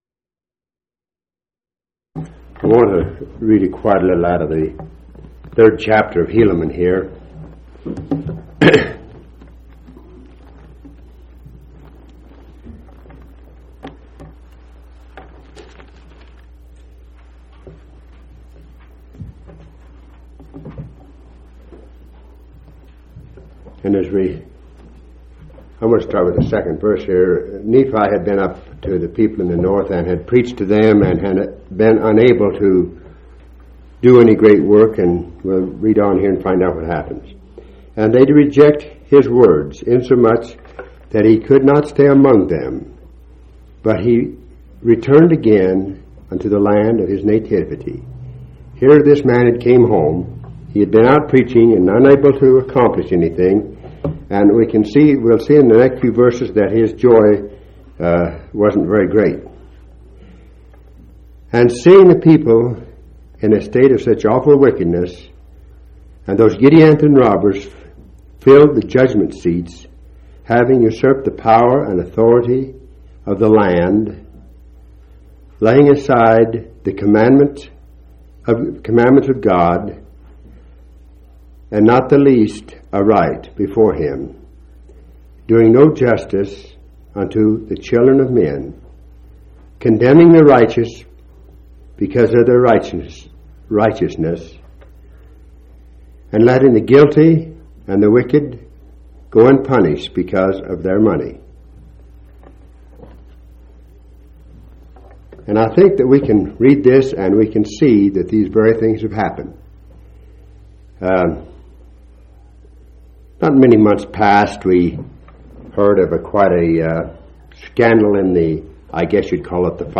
9/21/1986 Location: Grand Junction Local Event